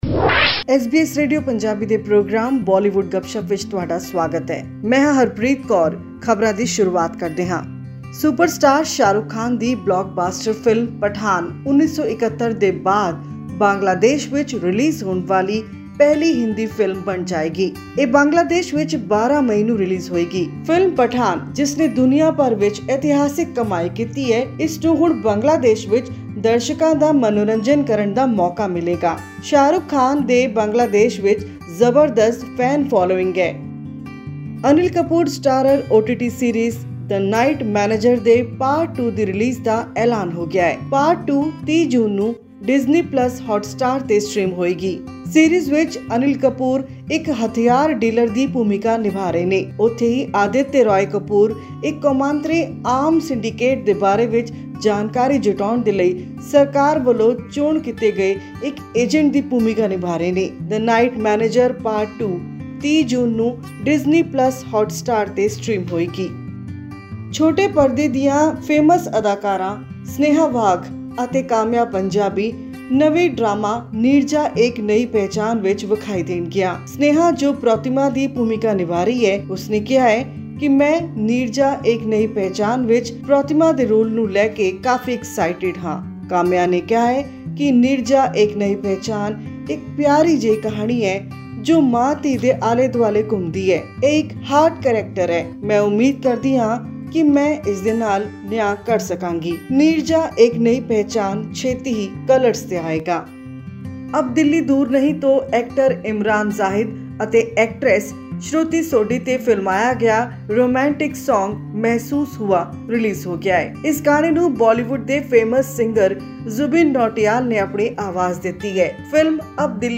Bollywood actor Jimmy Shergill’s upcoming crime thriller 'Aazam' revolves around the succession of mafia dons of Mumbai. This and much more in our weekly news bulletin from Bollywood.